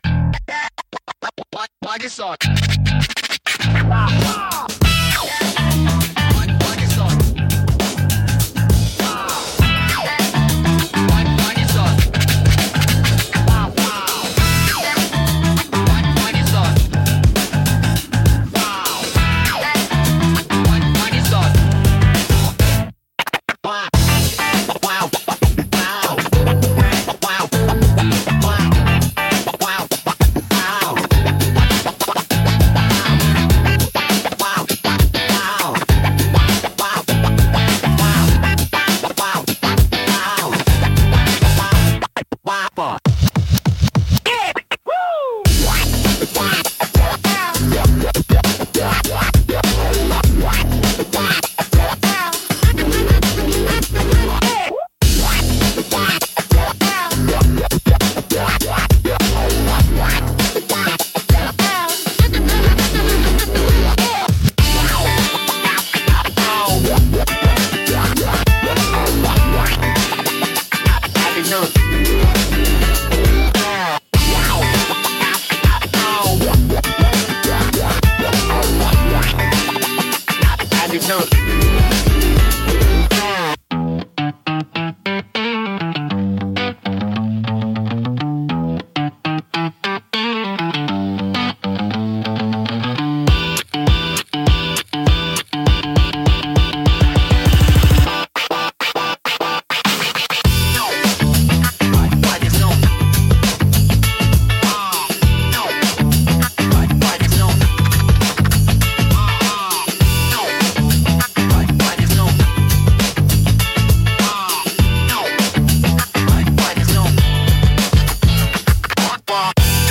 若々しく自由なエネルギーを感じさせ、都会的でポップな空間作りや動画配信の明るい雰囲気づくりに効果的です。